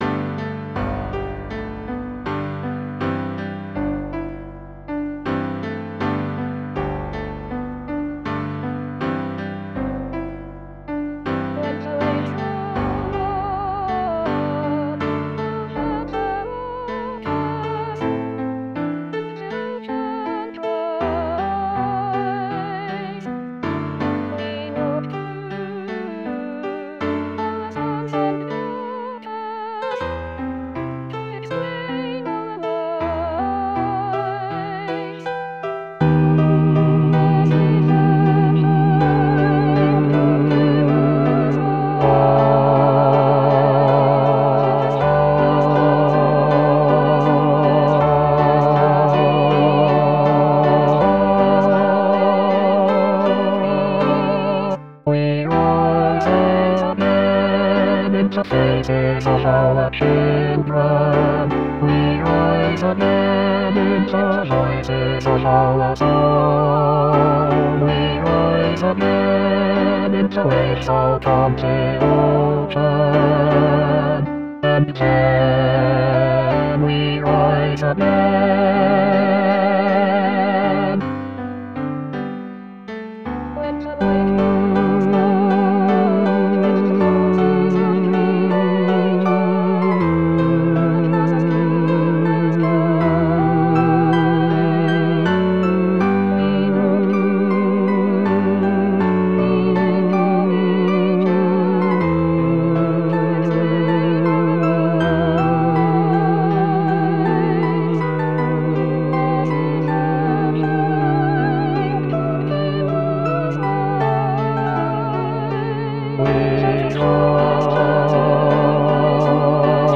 Bass Bass 2